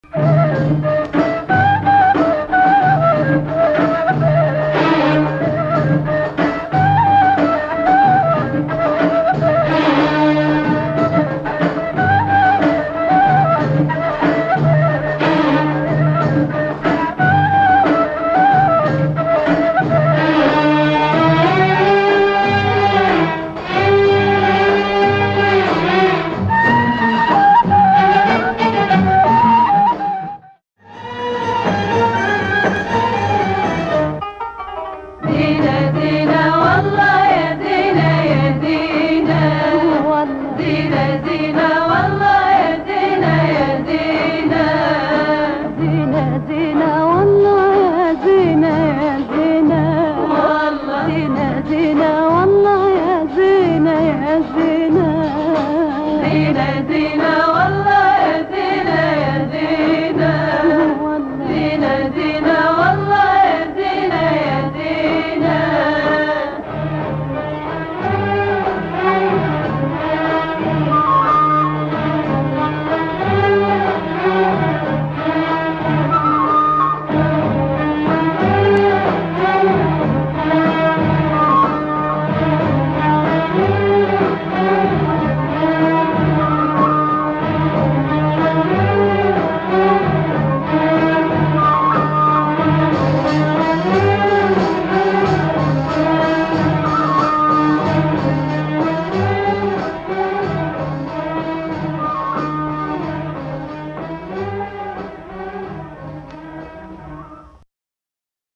Hijaz 1
melody uses both step below tonic, and up to 6